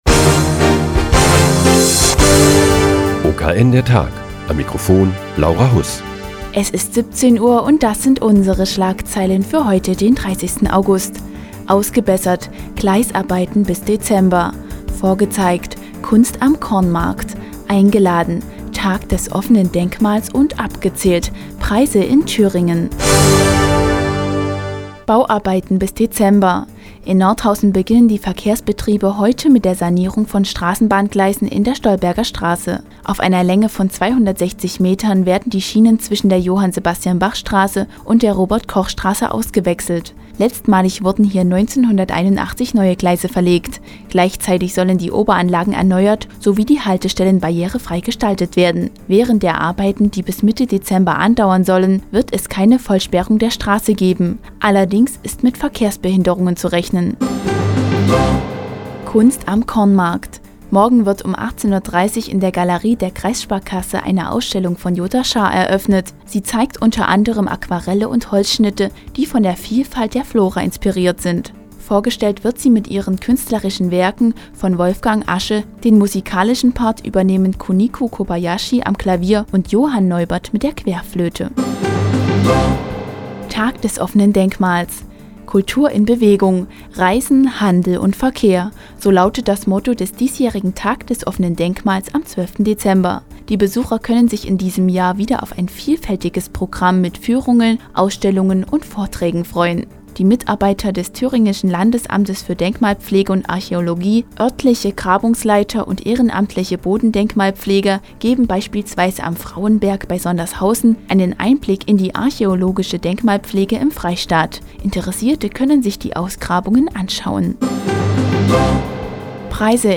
Die tägliche Nachrichtensendung des OKN ist nun auch in der nnz zu hören. Heute geht es um die Sanierung der Straßenbahngleisen in der Stolbergerstraße und den "Tag des offenen Denkmals" am 12. September.